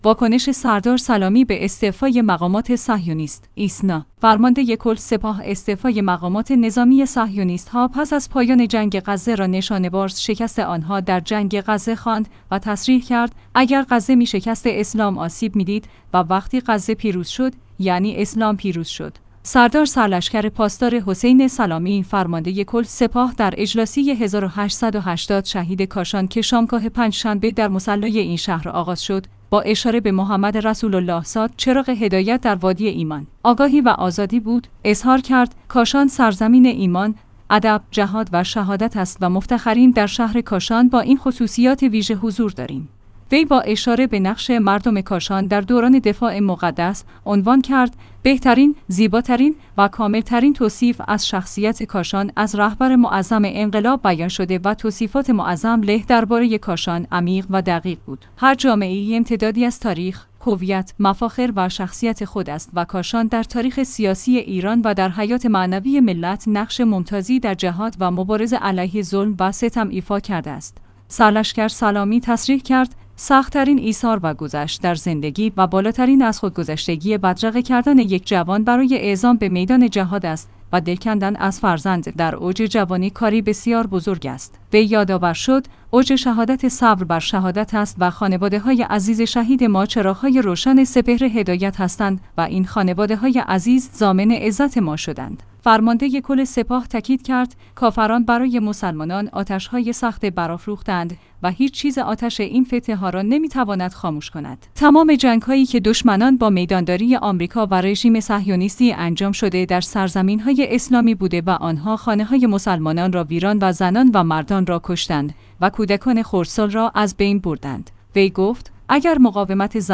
سردار سرلشکر پاسدار «حسین سلامی» فرمانده کل سپاه در اجلاسیه ۱۸۸۰ شهید کاشان که شامگاه پنجشنبه د